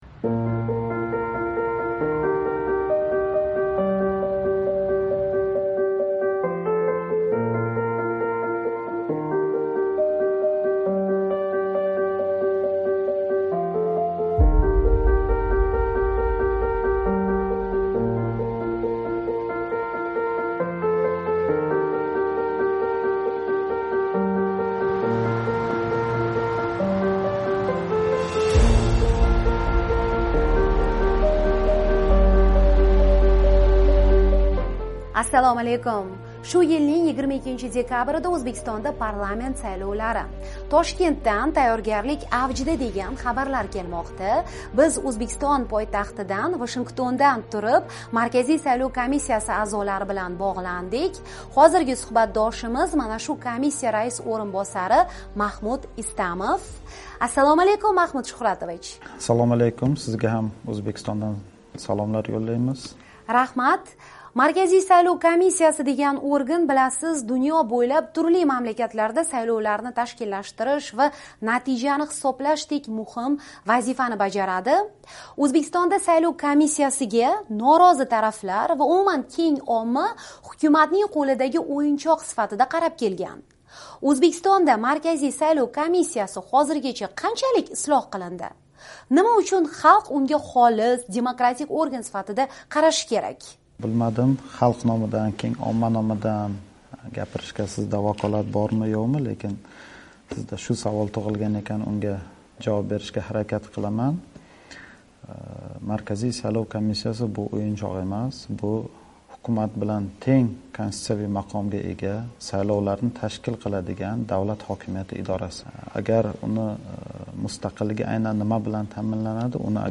O'zbekiston: Markaziy saylov komissiyasi raisi bilan suhbat